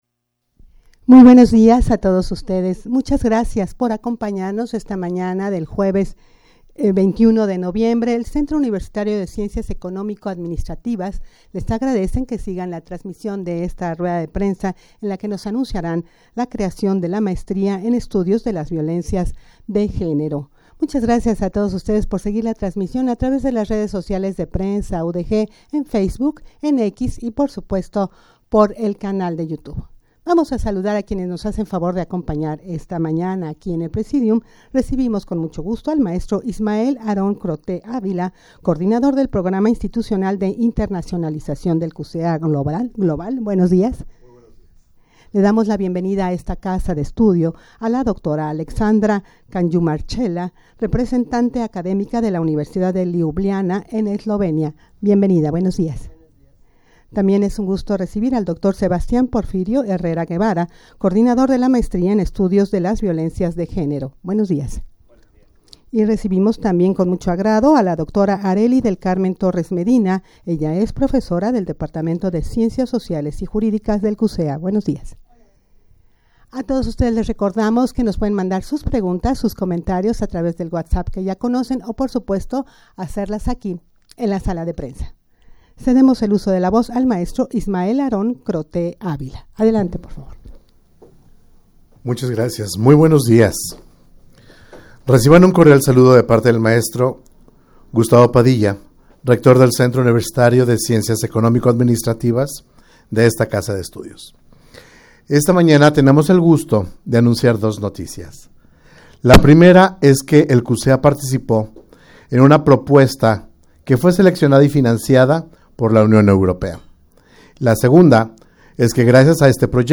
Audio de la Rueda de Prensa
rueda-de-prensa-para-anunciar-la-maestria-en-estudios-de-violencias-de-genero.mp3